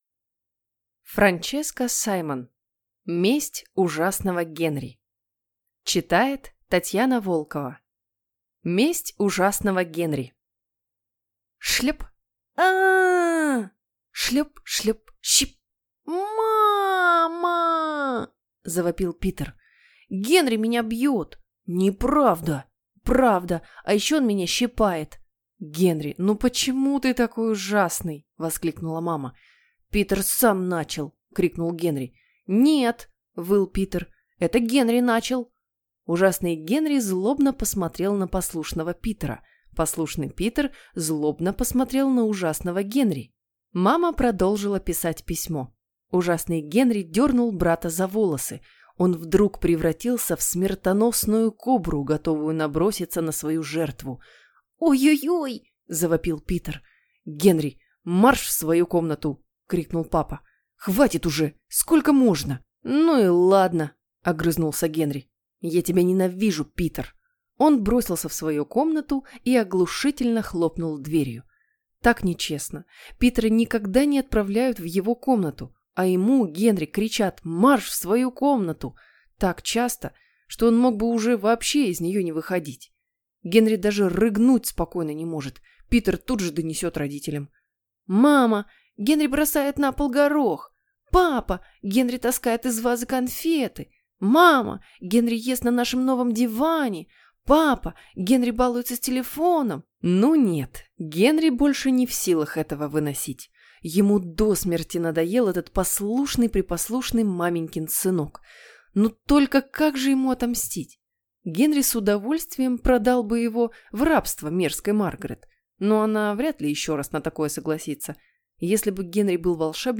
Аудиокнига Месть Ужасного Генри | Библиотека аудиокниг